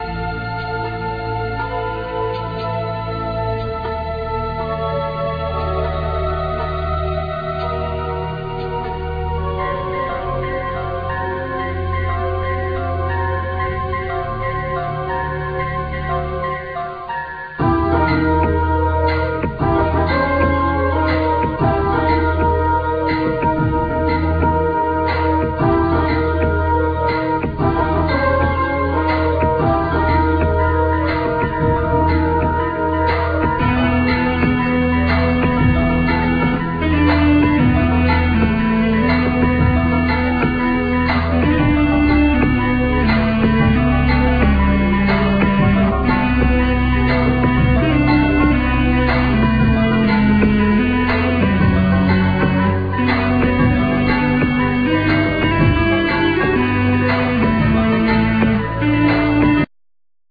Violin
Trumpet